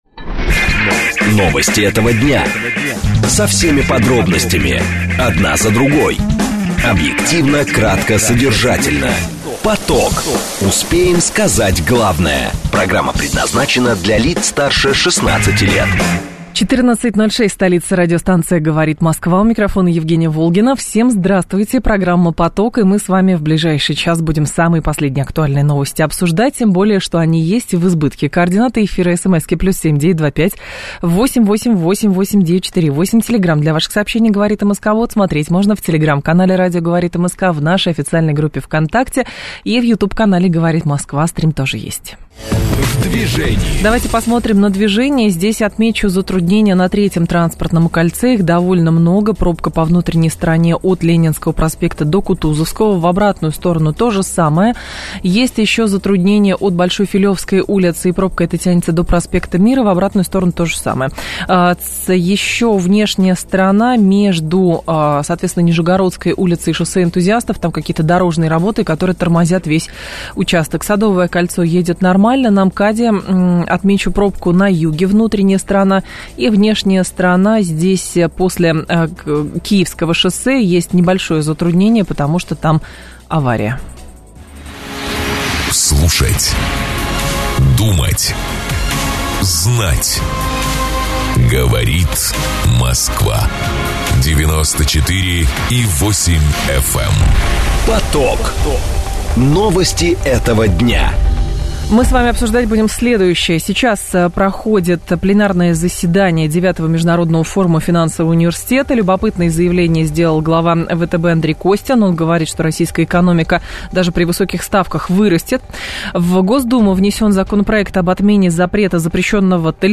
Сегодня прокомментировал эту ситуацию в прямом эфире радио "Говорит Москва", в передаче "Поток".